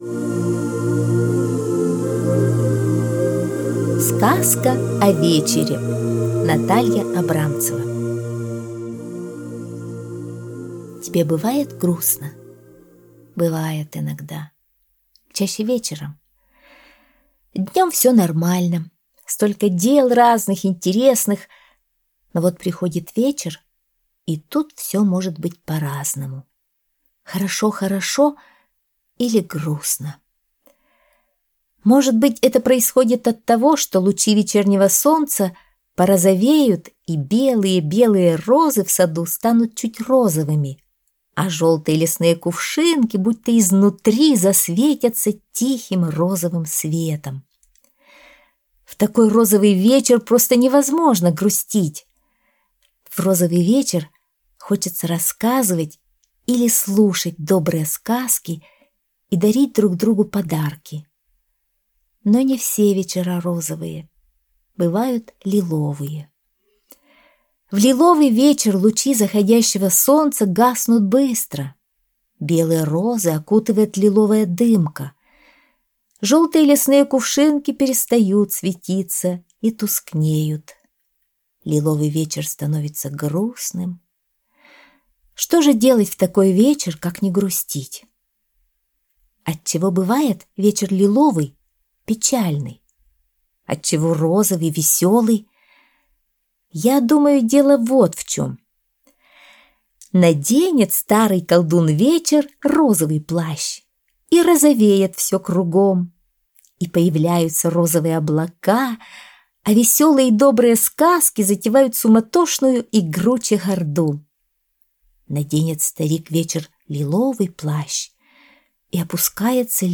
Сказка о вечере (аудиоверсия)
Аудиокнига в разделах